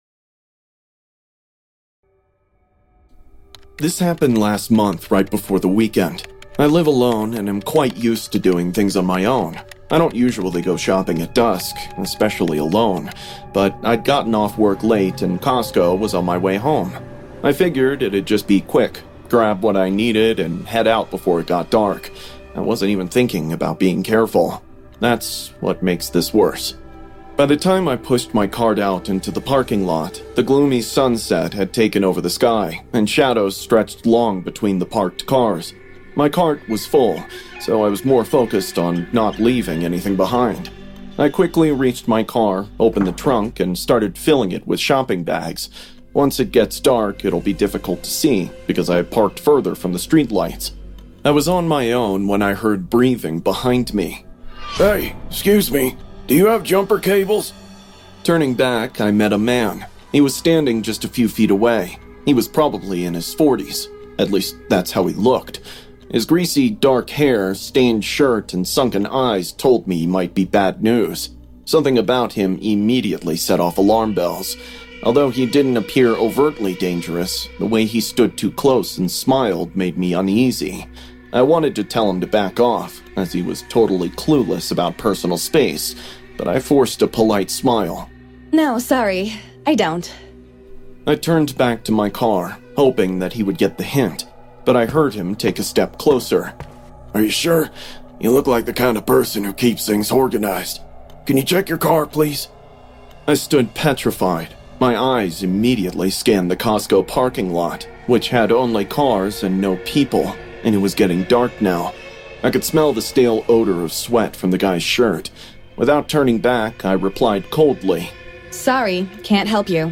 All advertisements are placed exclusively at the beginning of each episode, ensuring complete immersion in our horror stories without interruptions. Experience uninterrupted psychological journeys from start to finish with zero advertising breaks.